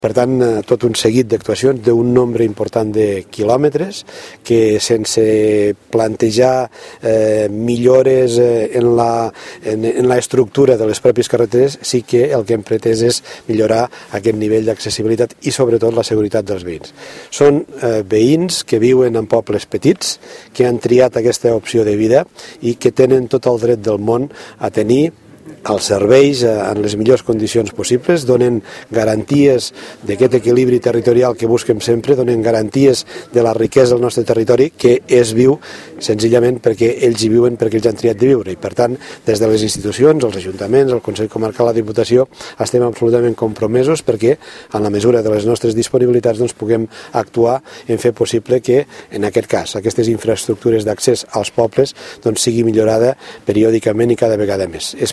Orcau, parc naturals Declaracions Sr. Reñé a la innauguració Crta. Orcau, inversió en carreteres